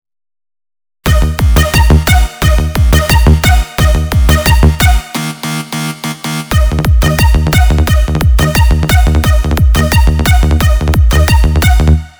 מקצב-טראק שבניתי על מדלי אשמח לחוות דעתכם!!!